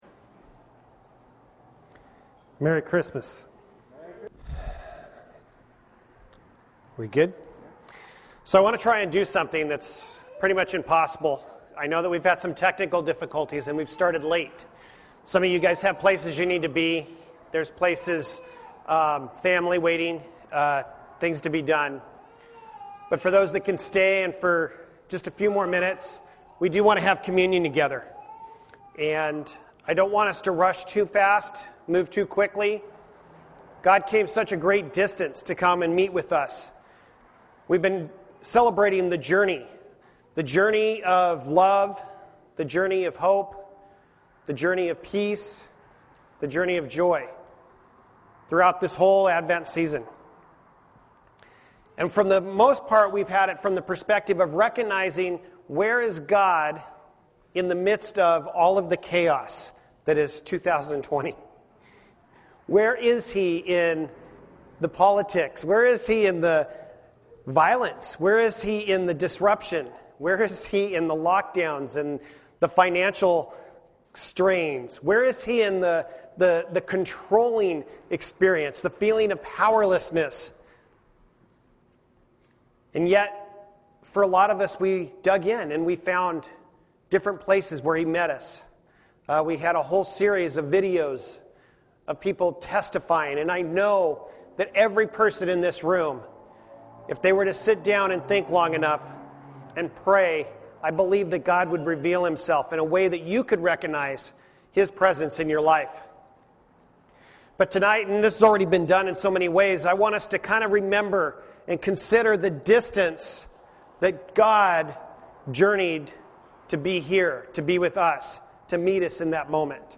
Passage: Hosea 11:9, Amos 5:1-3, John 1:1-14 Service Type: Special event